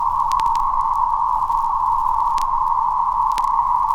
Stereo_Test_Tone_04.wav